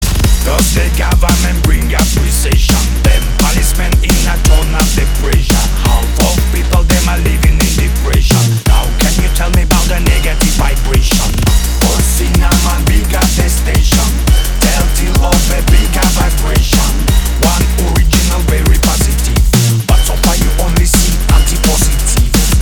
громкие
Electronic
drum n bass
breakbeat